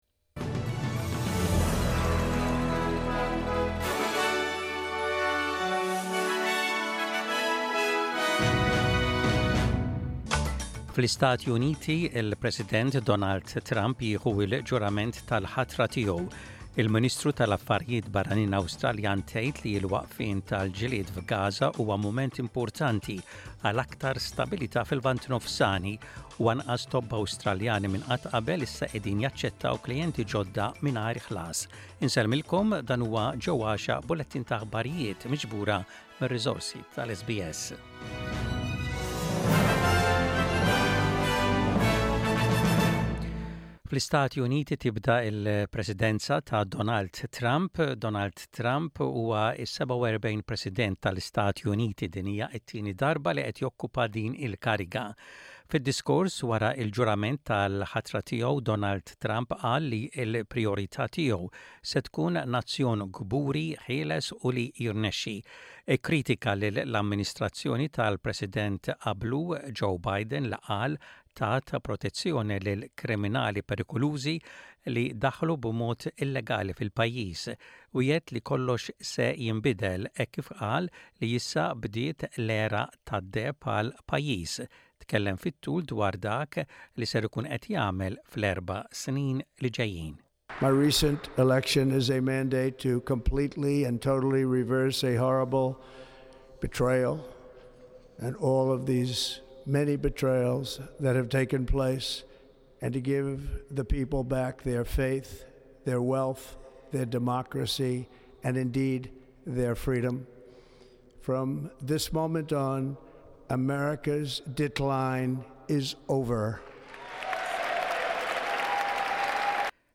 Aħbarijiet bil-Malti: 21.01.25